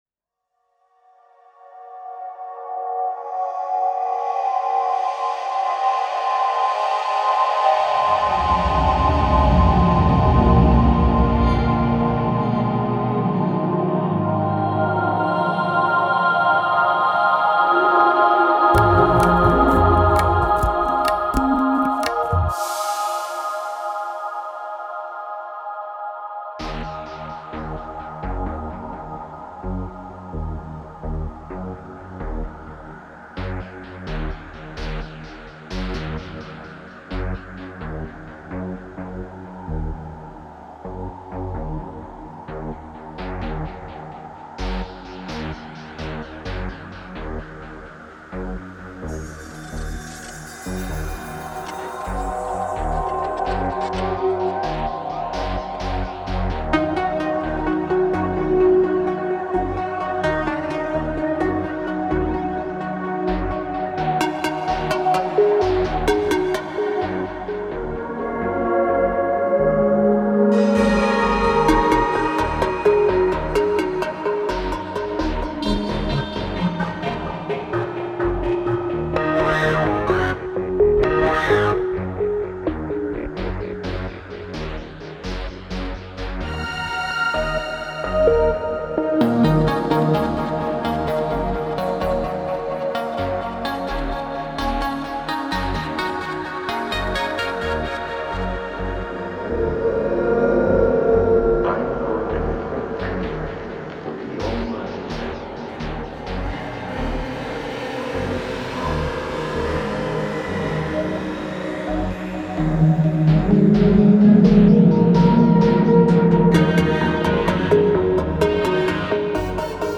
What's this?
Genre: Psybient.